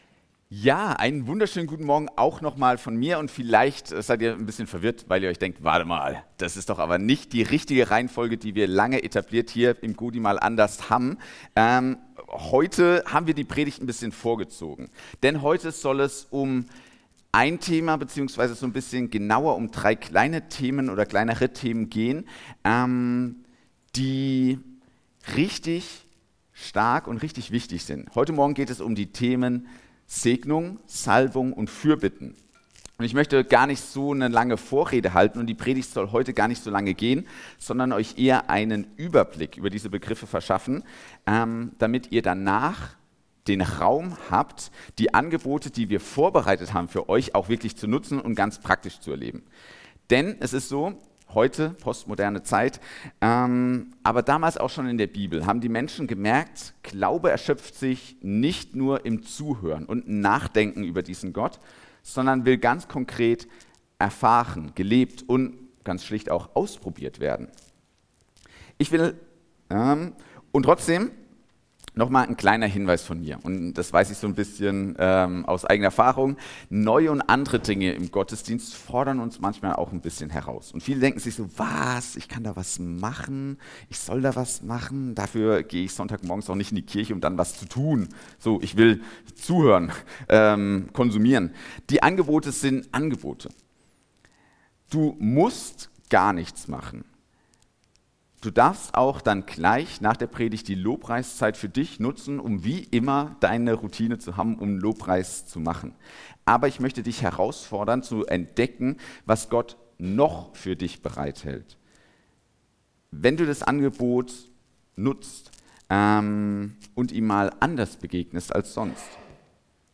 Segnung, Salbung & Fürbitte ~ Predigten - Gottesdienst mal anders Podcast